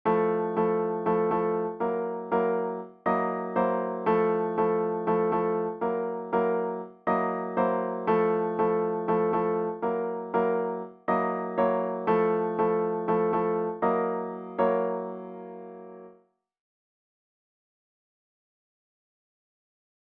イントロ
下記は4ビートの曲をボサノバにするときのイメージ。
4ビートのものと王道のものとのノリに注意しながら、
半音上がったり下がったりするだけの簡単なものでもボサノバのいい感じのノリで出すのがかなり難しいです